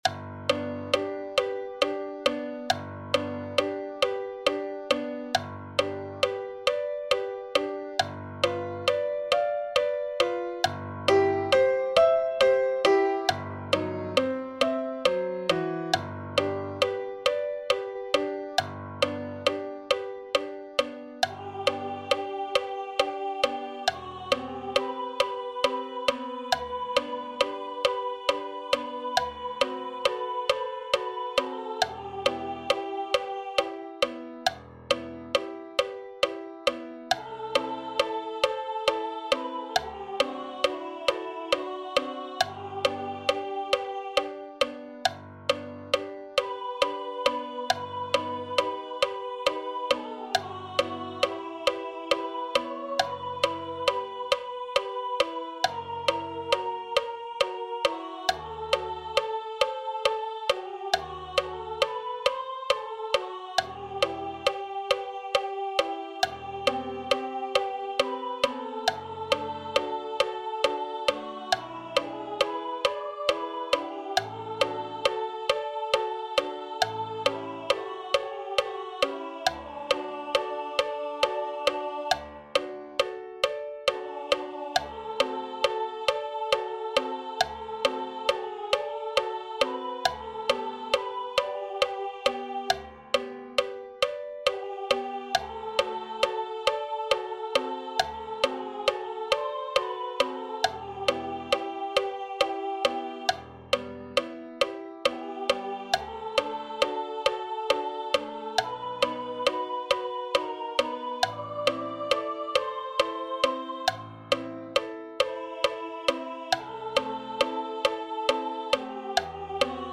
This arrangement is for low voice and piano.